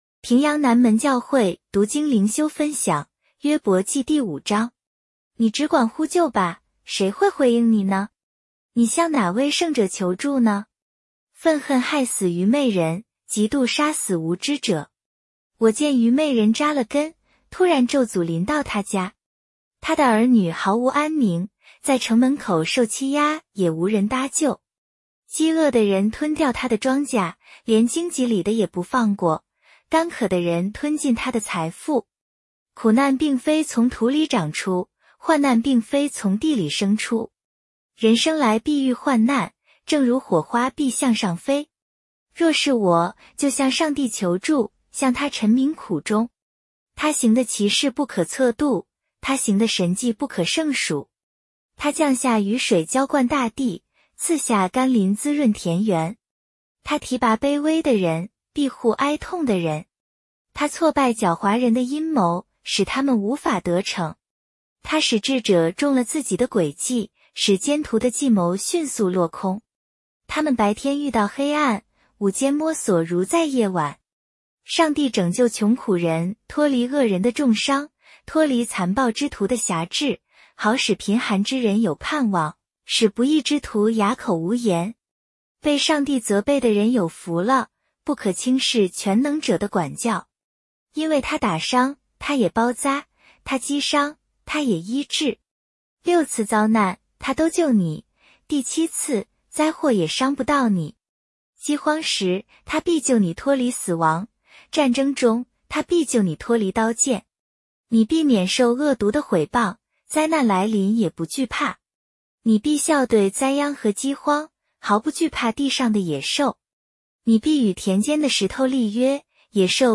普通话朗读——伯5